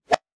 weapon_bullet_flyby_08.wav